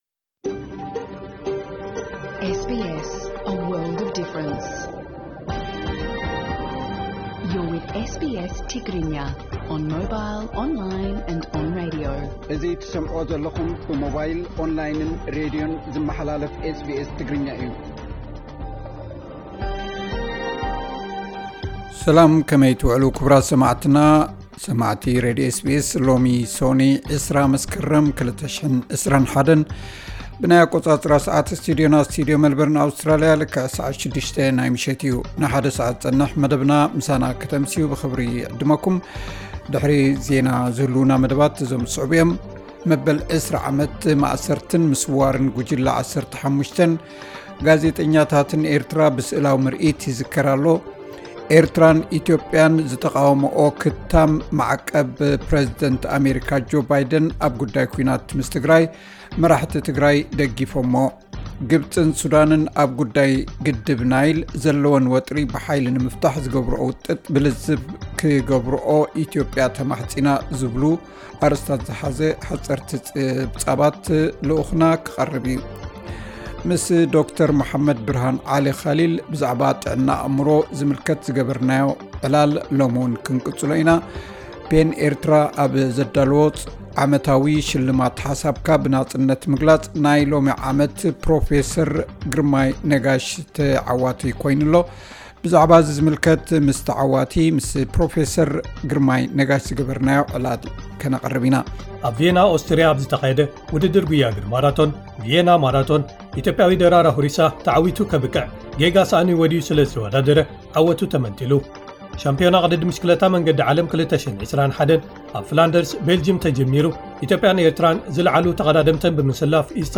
ዕለታዊ ዜና 20 መስከረም 2021 SBS ትግርኛ